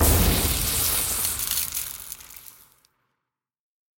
Sound / Minecraft / mob / zombie / remedy.ogg